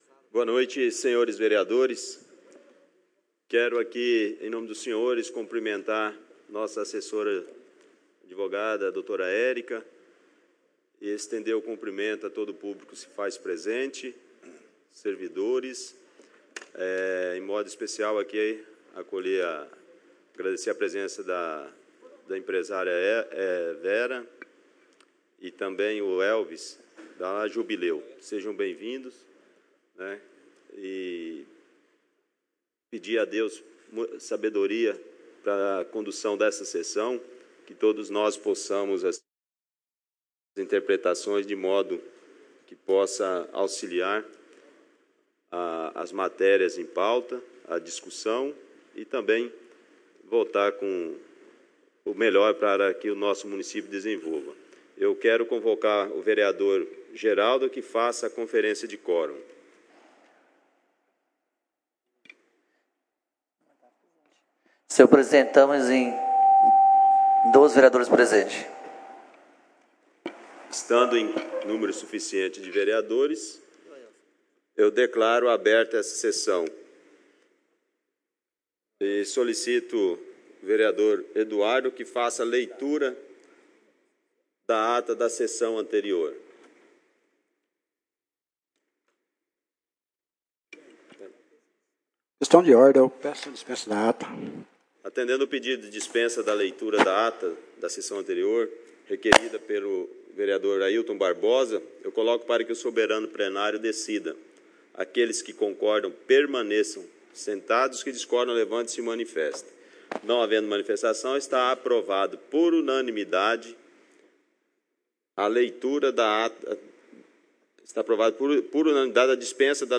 Áudio da Sessão Ordinaria realizada no dia 04/12/2017 as 20 horas no Plenário Henrique Simionatto.